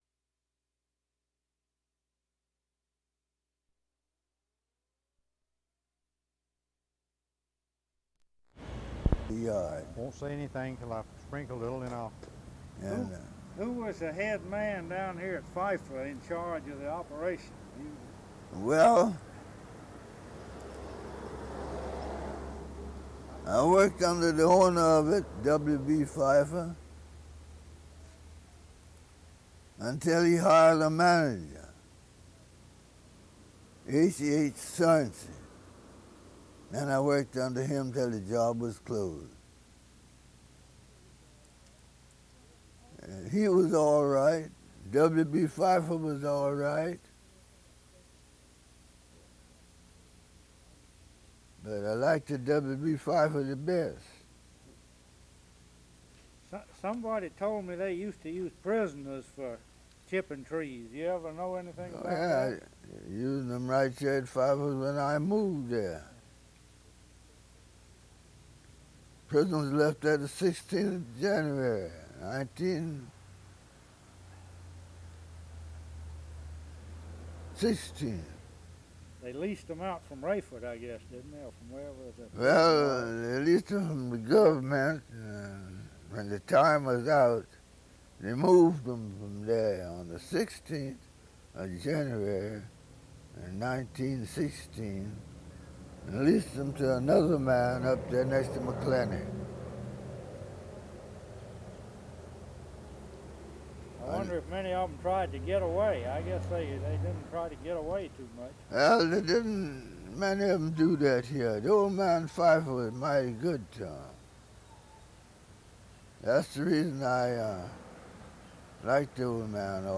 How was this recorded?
Digitized from VHS tape(s) DVD 1002.55c Valdosta State University Archives and Special Collections: Video Collection, 2020.